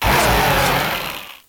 Cri de Minotaupe dans Pokémon Noir et Blanc.